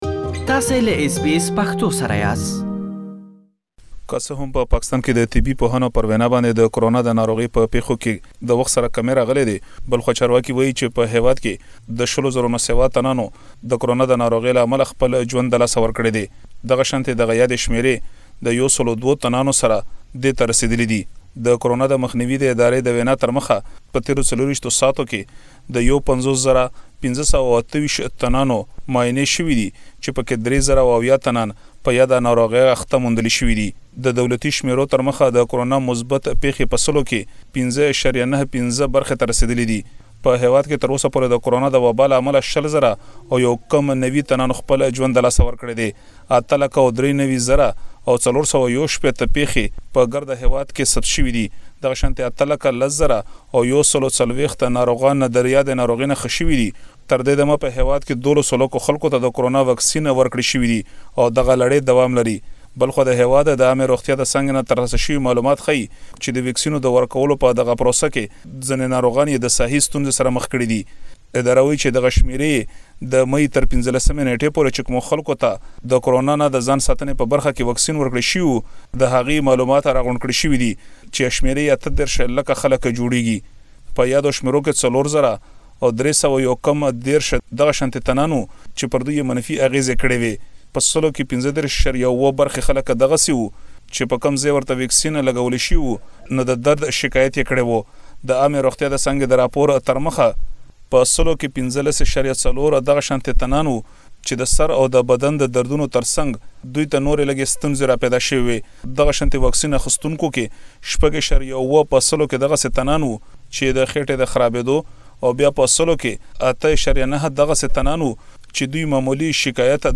له پيښور څخه رپوټ لري چې دلته يې اوريدلی شئ.